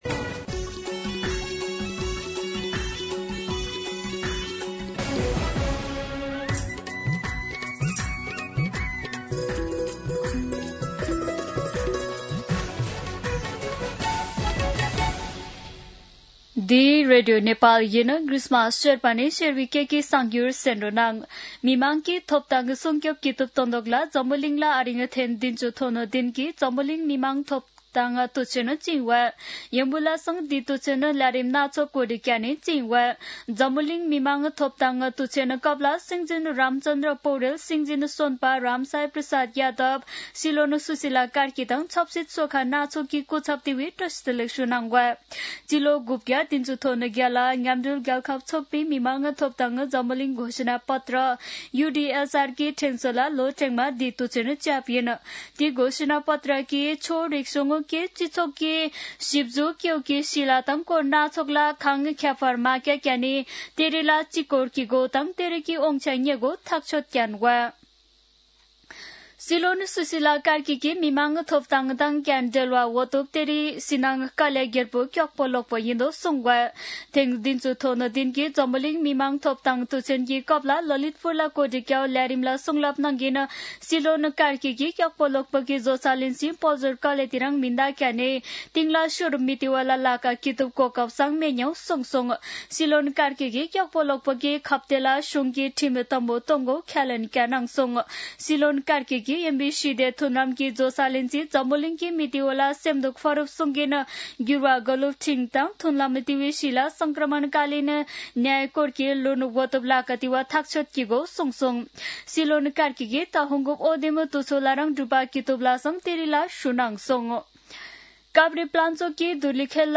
शेर्पा भाषाको समाचार : २४ मंसिर , २०८२
Sherpa-News-08-24.mp3